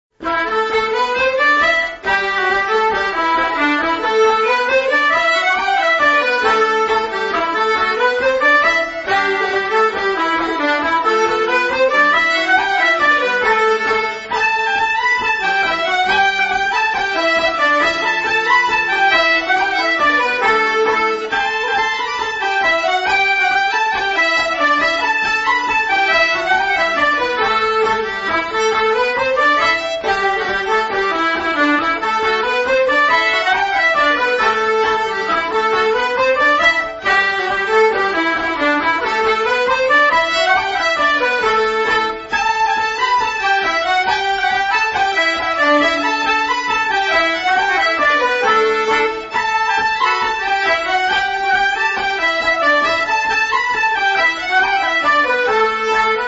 Polka en la dorien partition : ComBook1-page-36.pdf (29 kB) grille guitare : G_ballydesmond polka.pdf (9064) audio : 64 Ballydesmond No. 2.mp3 (333,5 kB) lien youtube (ballydesmond2+ballydesmond1+egan's polka)